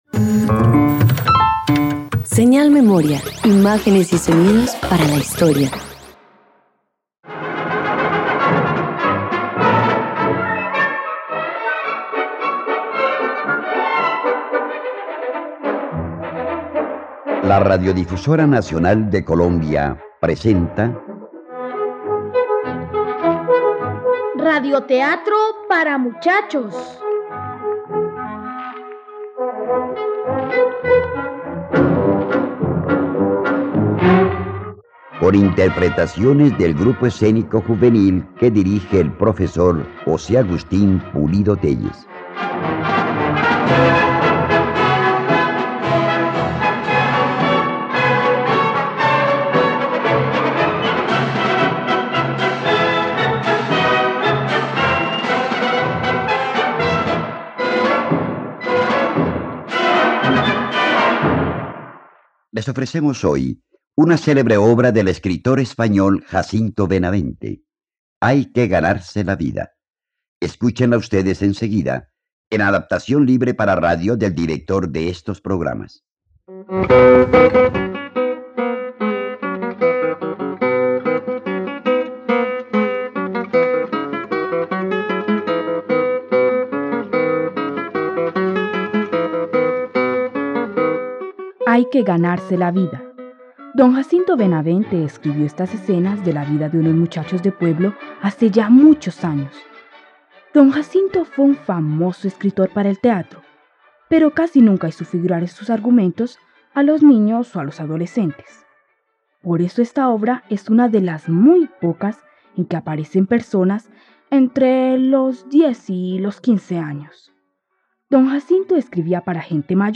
Hay que ganarse la vida - Radioteatro dominical | RTVCPlay